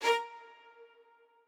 strings1_6.ogg